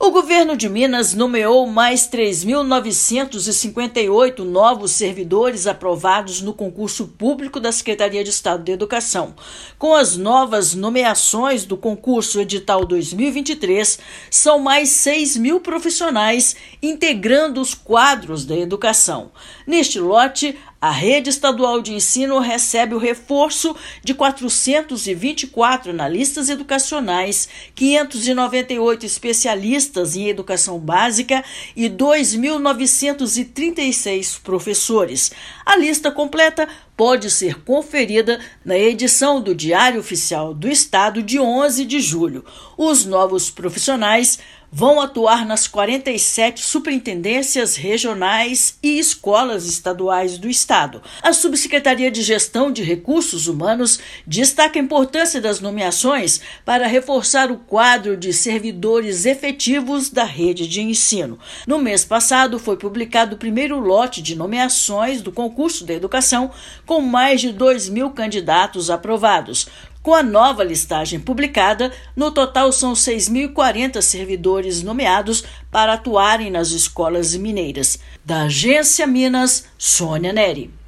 [RÁDIO] Estado nomeia mais 3,9 mil servidores aprovados no concurso da Educação
Lote de nomeações, publicado nesta quinta-feira (11/7), inclui cargos de analistas educacionais - inspetores, especialistas e professores de educação básica; no total, são mais de 6 mil profissionais reforçando os quadros da Educação. Ouça matéria de rádio.